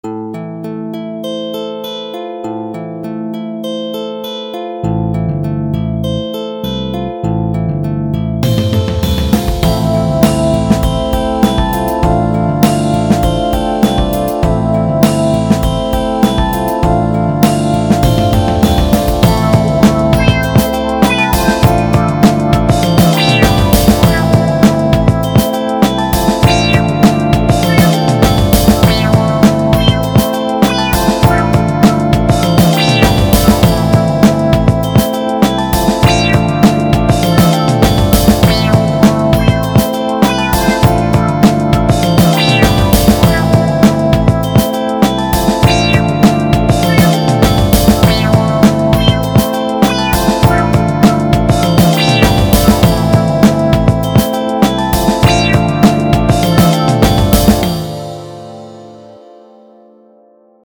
Efendim dün akşam yaptığım böyle jazz'a benzeyen bir olayı size de aktarmak isterim... evet olay bir mp3 dosyası :)